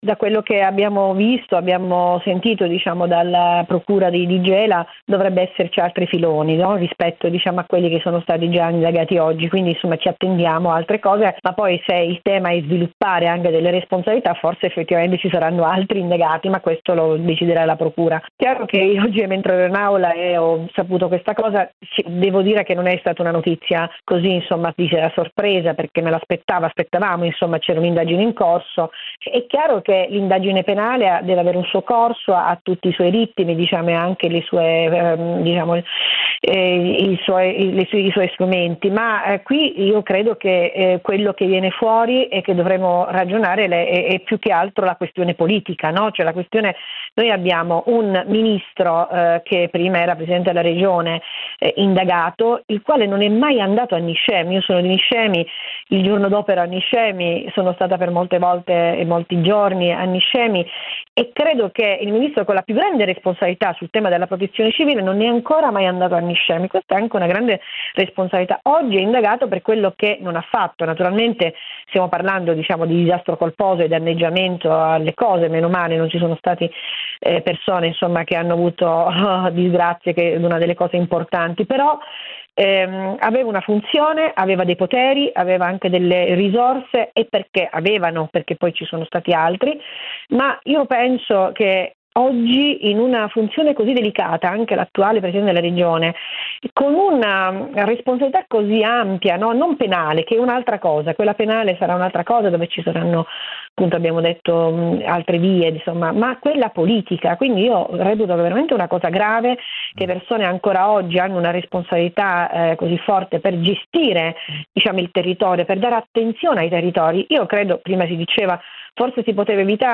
Niscemi, dopo le immagini della frana che hanno fatto il giro del mondo, gli annunci e il clamore, arrivano i primi risultati delle indagini e rimane il problema, il silenzio. Enza Rando, deputata del Pd e già candidata sindaca e consigliera comunale di Niscemi racconta e commenta gli sviluppi che rivelano le inchieste della magistratura sulla frana, i vuoti del governo e le responsabilità politiche di una vicenda che non è destinata a chiudersi (e nemmeno a risolversi con un processo).